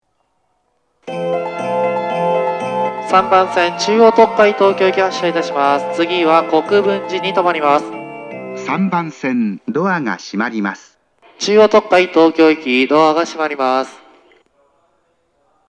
当駅は収録環境が悪く、なかなかメロディーを綺麗に収録できません。
発車メロディー
0.7コーラス（フルコーラス）です。